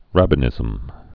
(răbə-nĭzəm)